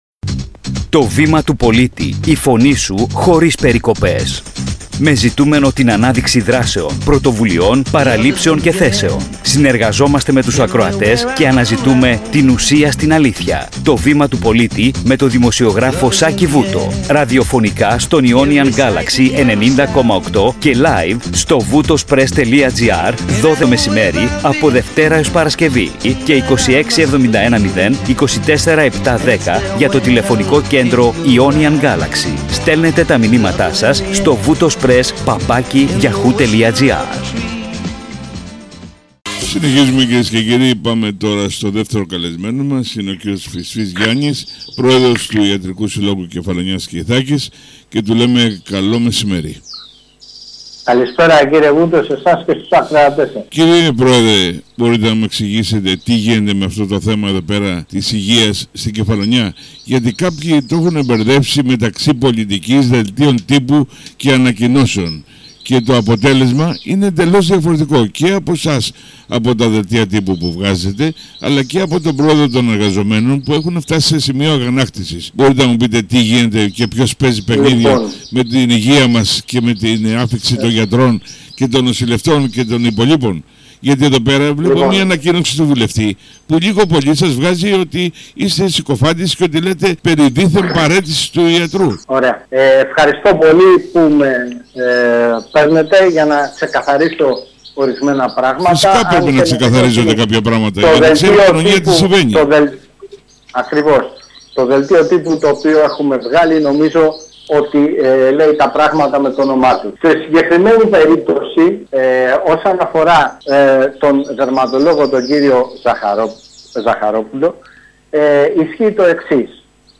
Δημοσιογραφικό Κείμενο – Συνέντευξη σε μορφή Ερώτηση-Απάντηση: